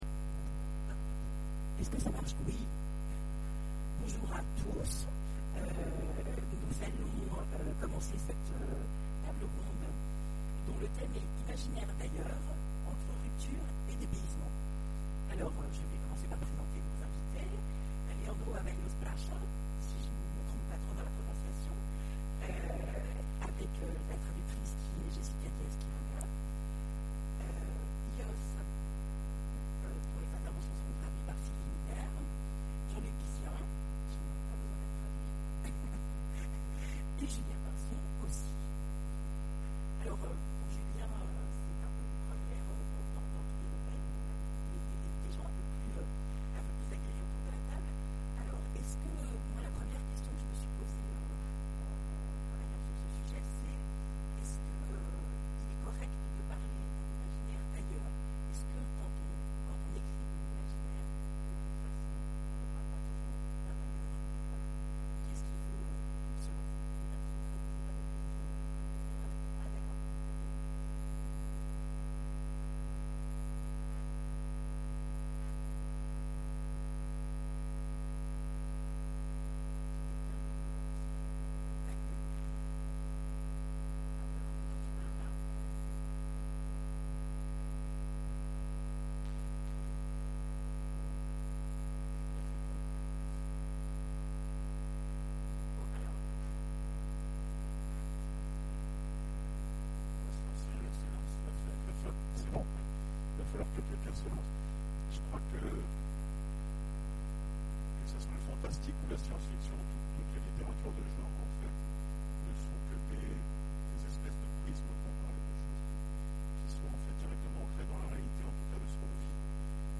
Imaginales 2014 : Conférence Imaginaires d'ailleurs, entre dépaysement et rupture...